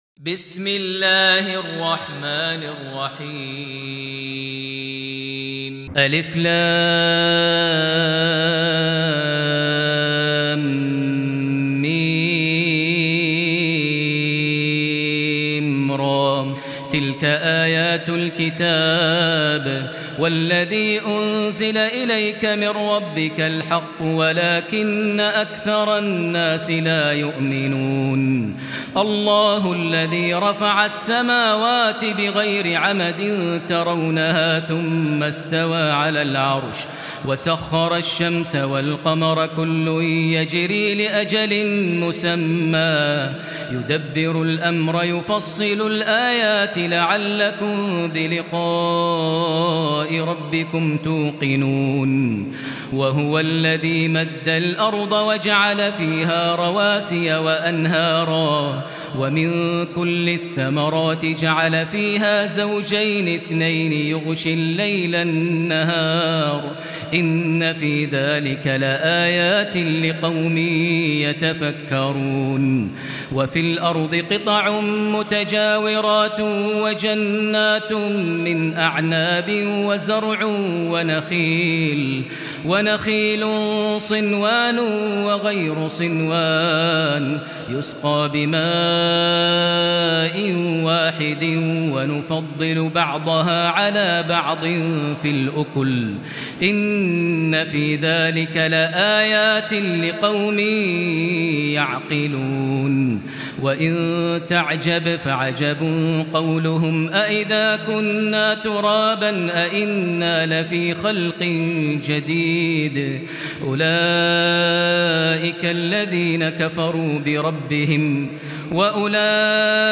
سورة الرعد > مصحف الشيخ ماهر المعيقلي (2) > المصحف - تلاوات ماهر المعيقلي